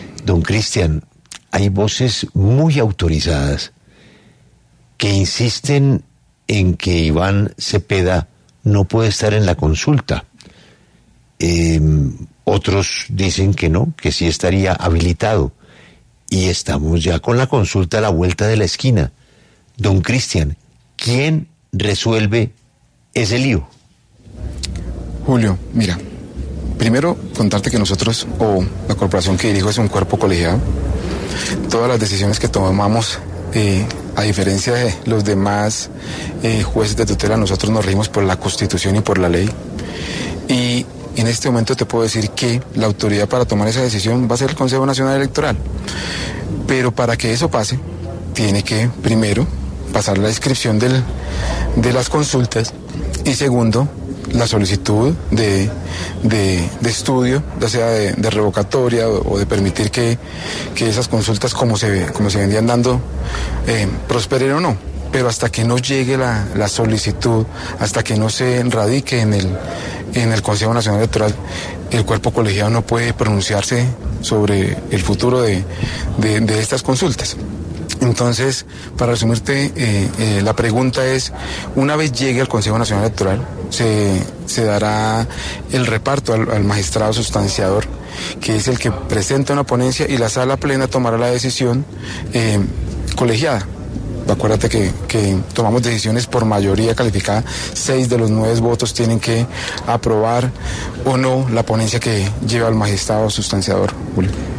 Cristian Quiroz, presidente del Consejo Nacional Electoral (CNE), pasó por los micrófonos de 6AM W y se refirió a la advertencia de la Misión de Observación Electoral (MOE), sobre que el precandidato Iván Cepeda no podría participar en las consultas de marzo.